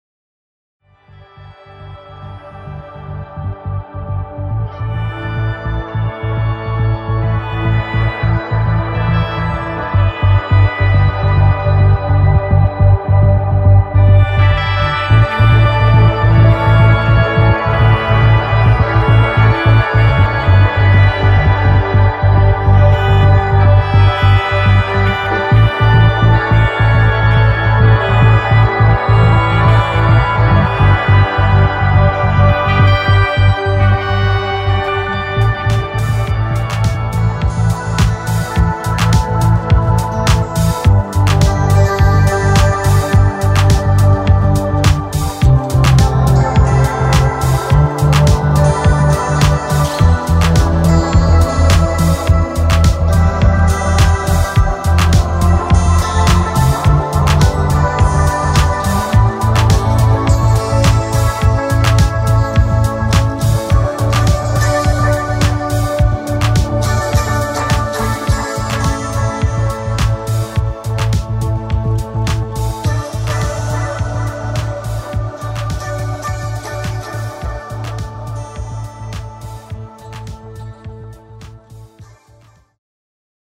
Μινι- tutorial για το πως φτιαχνουμε κιθαρες ταξιδιαρικες μπλιμπλικατες.
Τι εννοουμε παραλληλη επεξεργασια: Η κιθαρα μας δε θα τροφοδοτει ενα μονο καναλι, αλλα πολλαπλα.